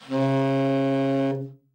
Index of /90_sSampleCDs/Giga Samples Collection/Sax/TEN SAX SOFT